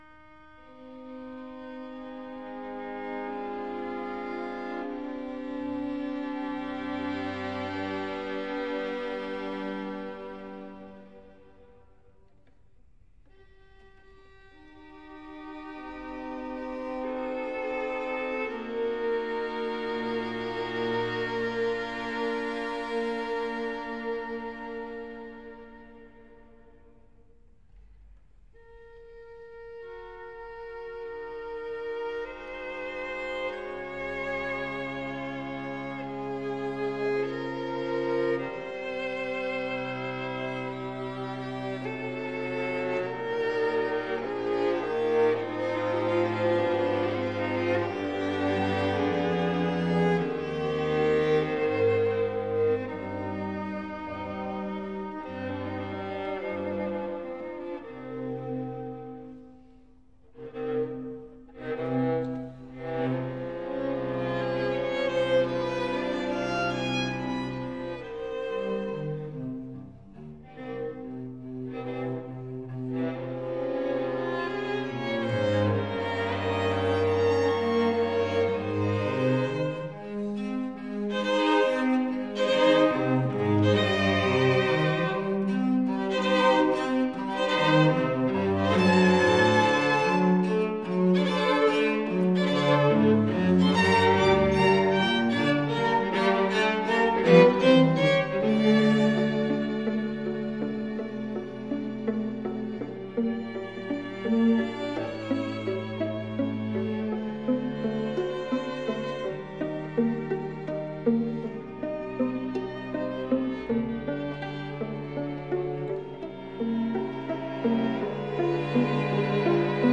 For 2 Violins, Viola & 2 Cellos or 2 Violins 2 Violas & Cello
The music is solemn but not funereal.